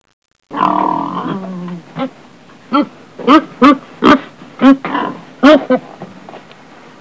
Pig 2
PIG_2.wav